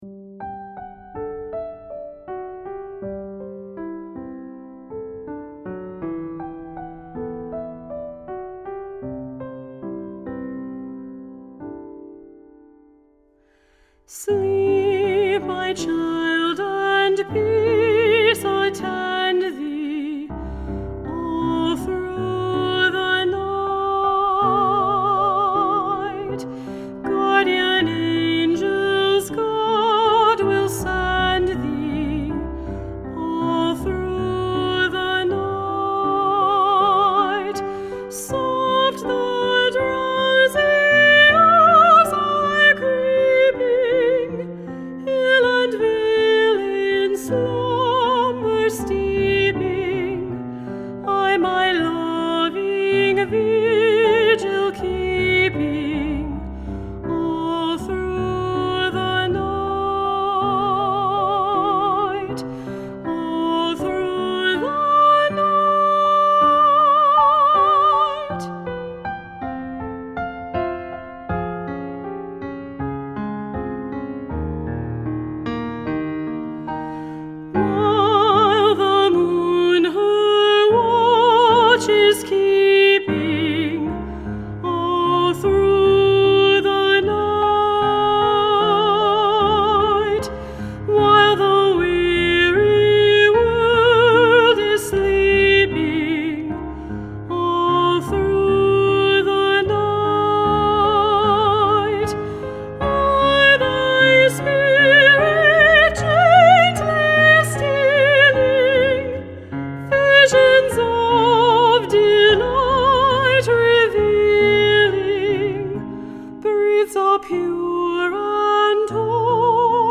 10 Folk Songs and Spirituals
Voicing: Medium-High Voice